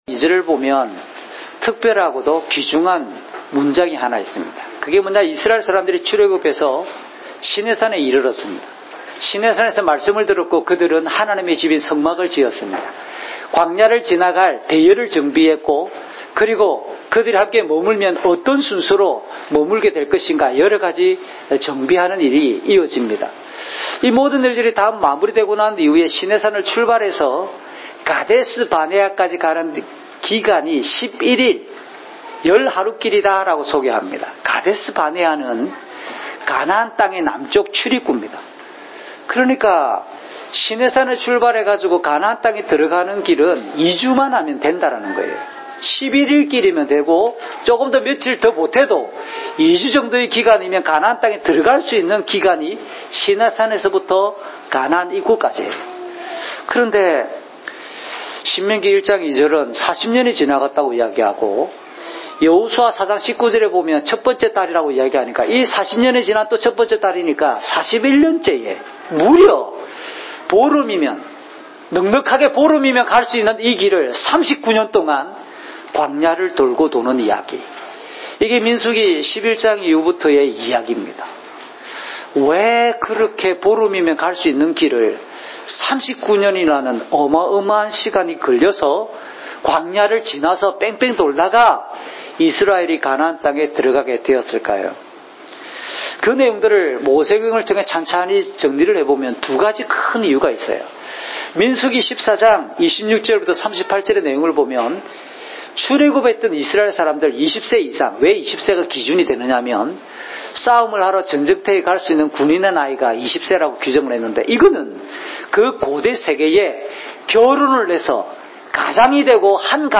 주일 목사님 설교를 올립니다.